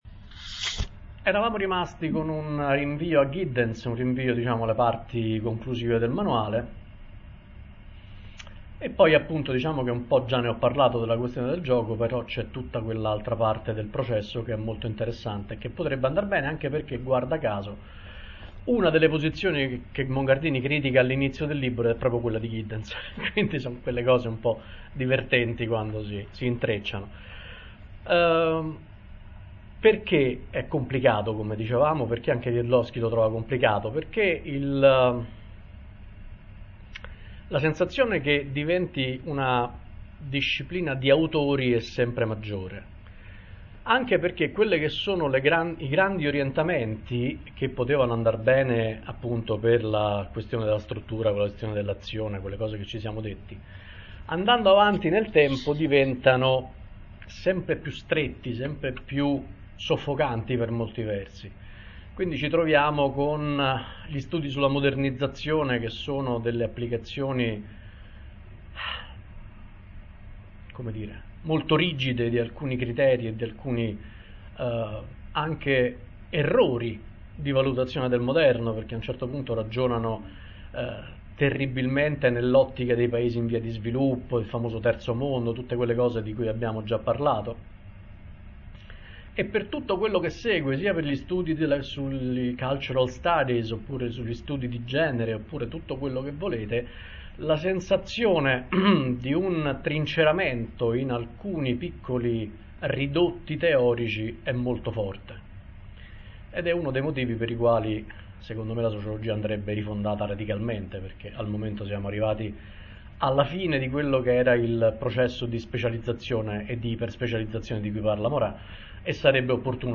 Registrazioni delle lezioni